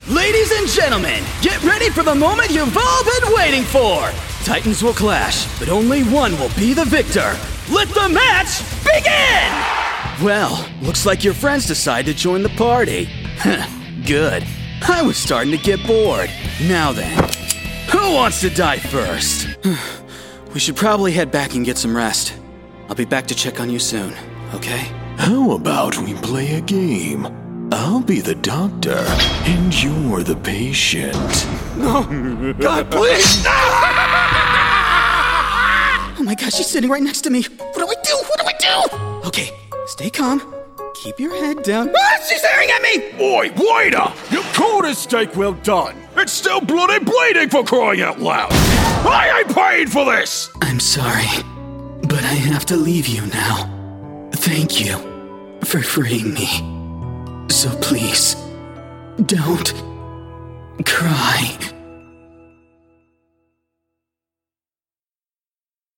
Teenager, Young Adult, Adult, Mature Adult
australian | character
cockney | character
irish | character
northern english | natural
russian | character
standard british | character
ANIMATION 🎬
warm/friendly
scream/yell
broadcast level home studio